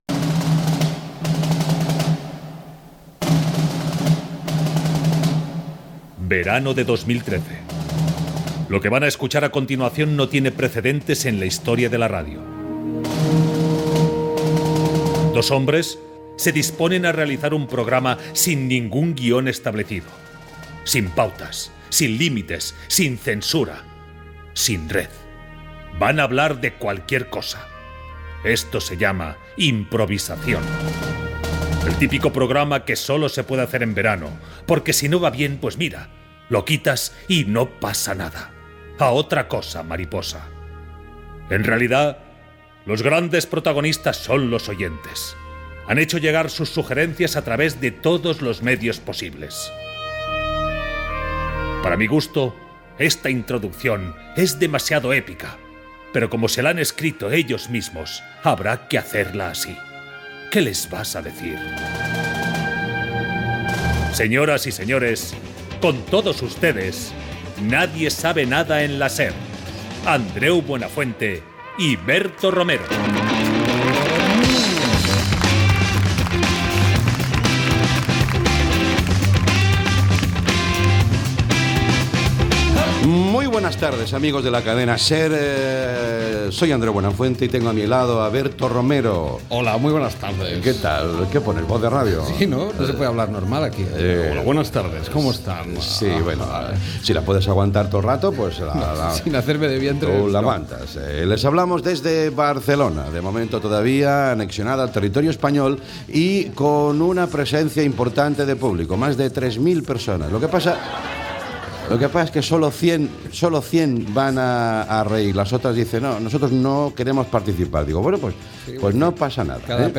Diàleg dels presentadors sobre temes diversos. Respostes a les propostes i preguntes plantejades per l'audiència Gènere radiofònic Entreteniment